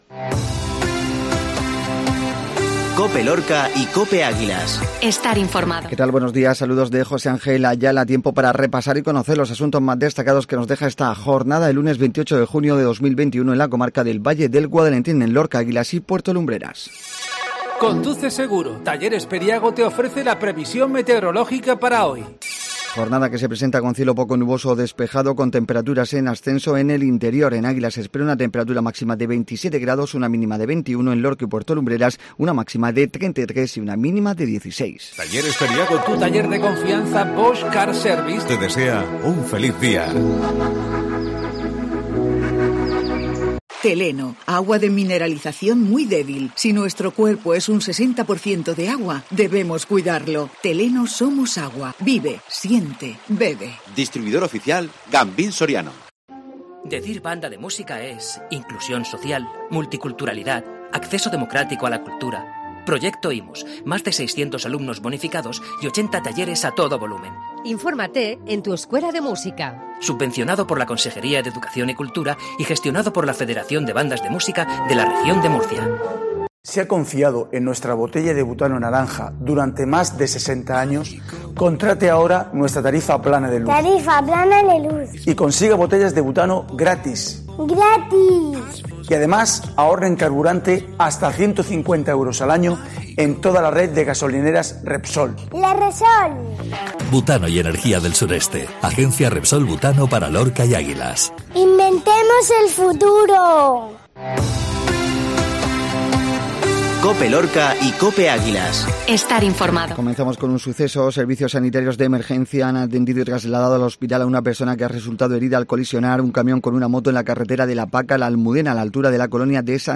INFORMATIVO MATINAL LUNES